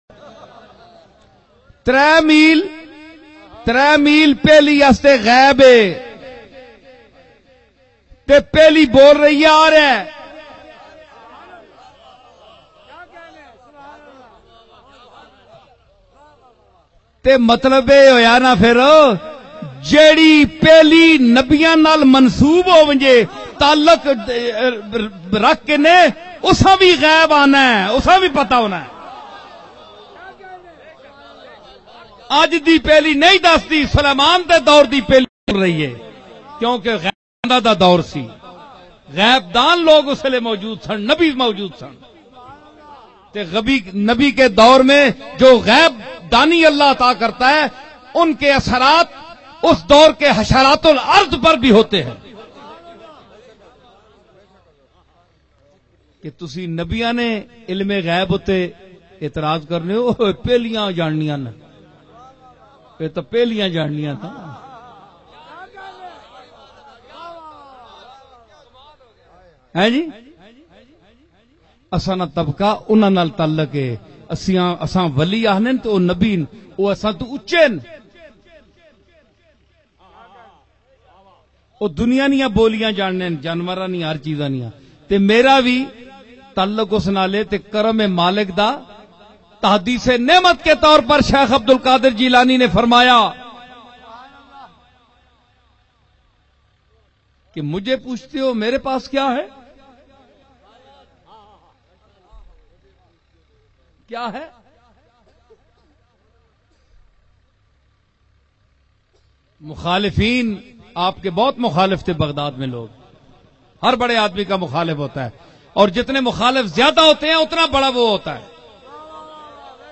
baba fareed byan